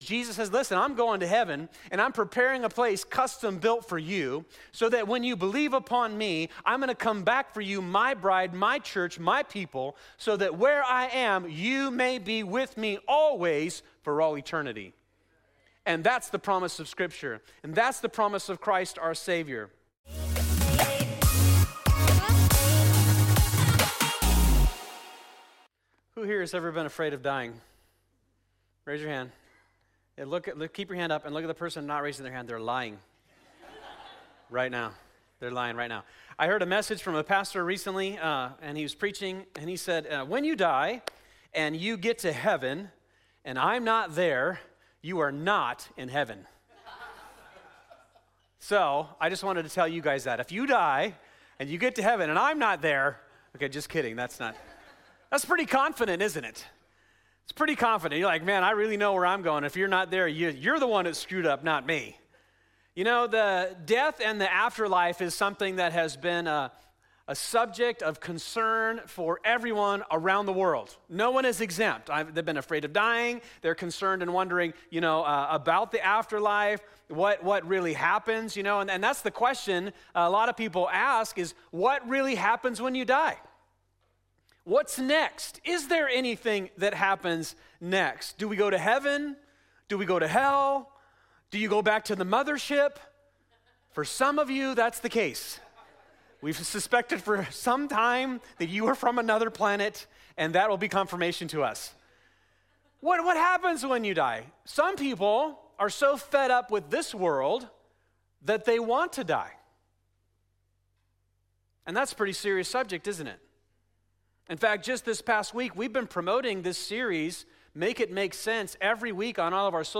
2025 Make It Make Sense Heaven Hell Love Sunday Morning Scripture: Luke 16:19-31 Download